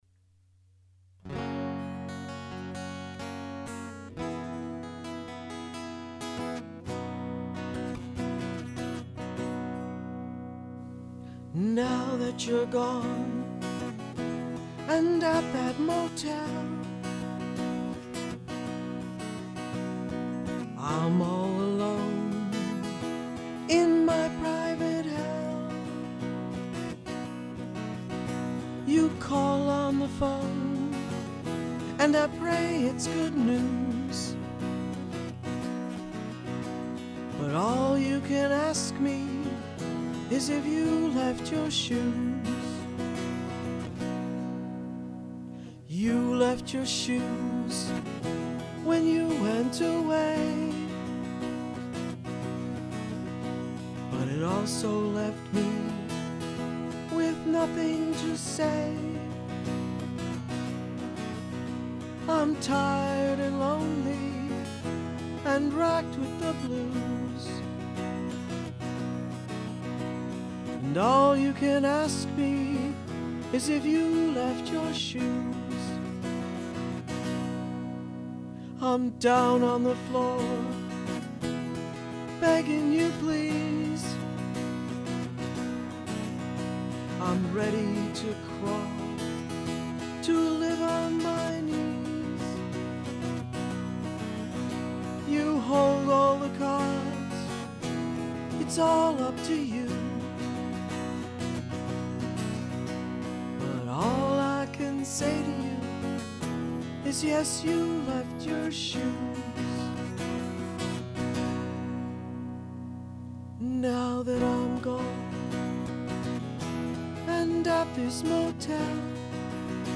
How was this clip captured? Recorded in Low-Fidelity December 2005-February 2006 at